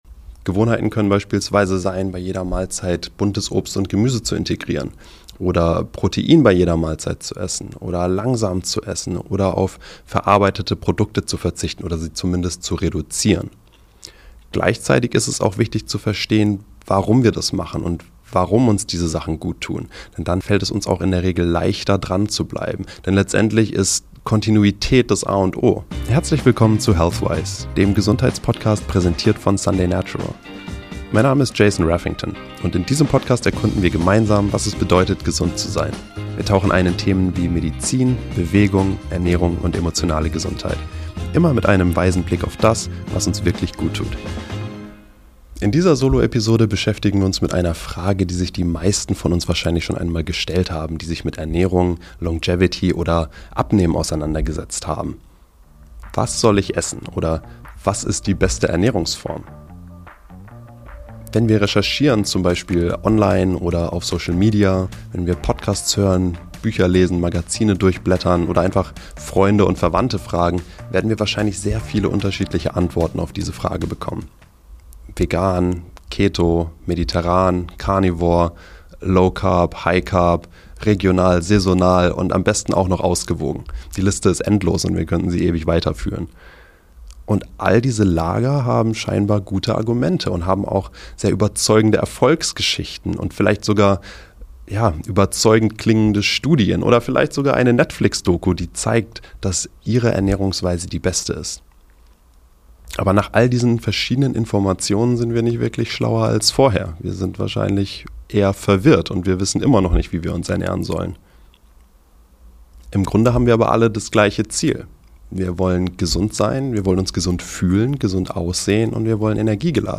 Willkommen bei HEALTHWISE, dem Podcast präsentiert von Sunday Natural, der sich intensiv mit der Frage auseinandersetzt, was wahre Gesundheit in unserer modernen Gesellschaft bedeutet. Jede Episode bietet wertvolle Einblicke und inspirierende Gespräche mit Expert*innen aus verschiedenen Gesundheitsbereichen.